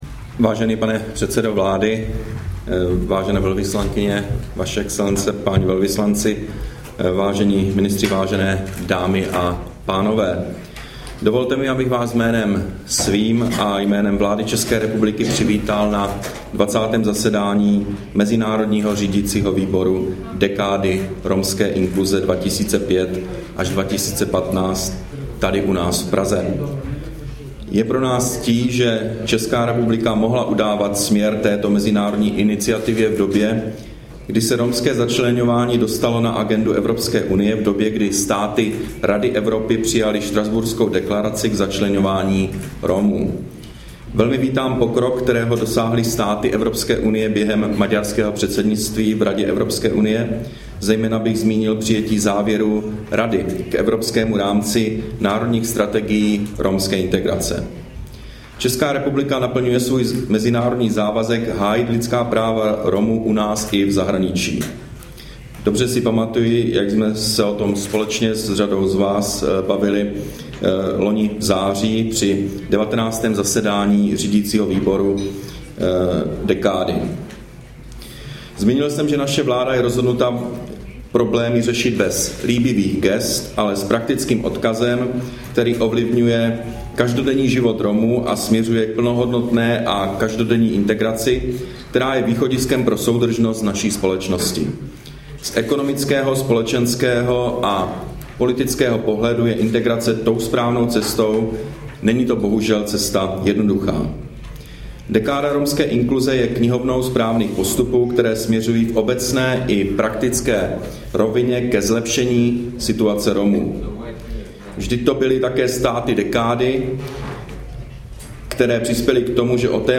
Projev premiéra Petra Nečase na 20. zasedání Mezinárodního řídícího výboru Dekády romské inkluze, 27. června 2011